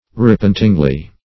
repentingly - definition of repentingly - synonyms, pronunciation, spelling from Free Dictionary Search Result for " repentingly" : The Collaborative International Dictionary of English v.0.48: Repentingly \Re*pent"ing*ly\, adv. With repentance; penitently.